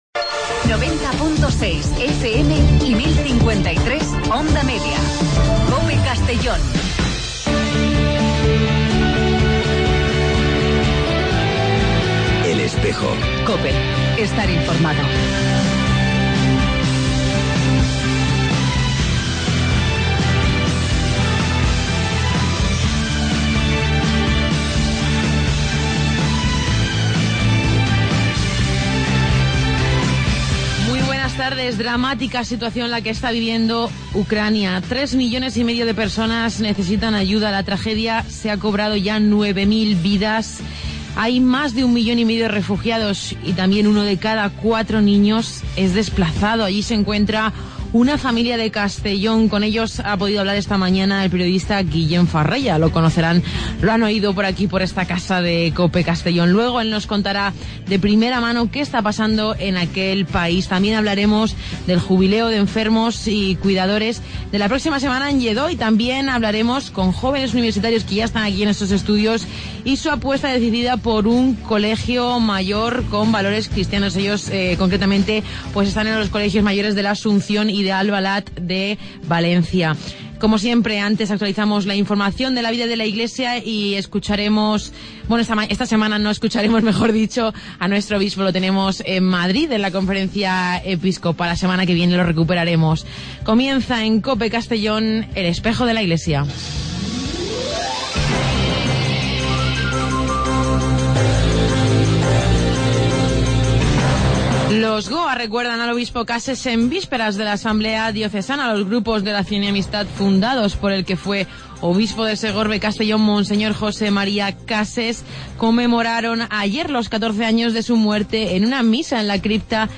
Redacción digital Madrid - Publicado el 22 abr 2016, 20:41 - Actualizado 18 mar 2023, 09:55 1 min lectura Descargar Facebook Twitter Whatsapp Telegram Enviar por email Copiar enlace El programa de radio de la diócesis de Segorbe-Castellón. Con entrevistas, información y el mensaje semanal de monseñor Casimiro López Llorente.